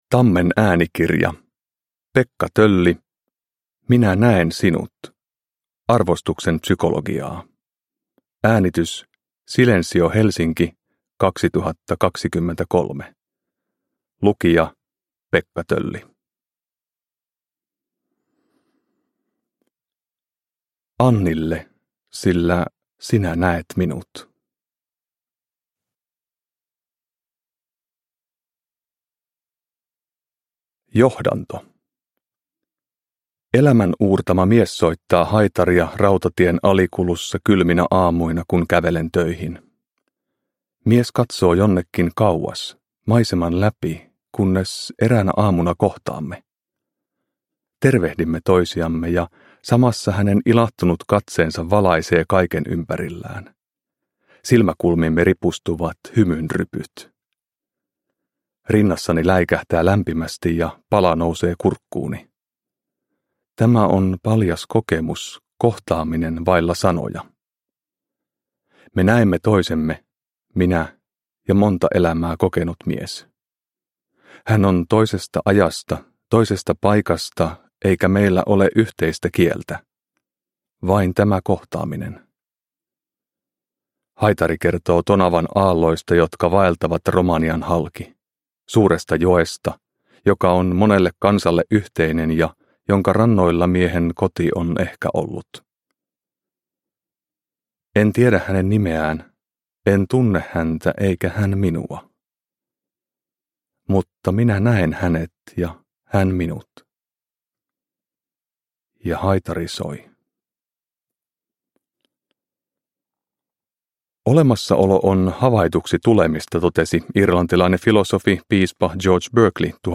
Minä näen sinut – Ljudbok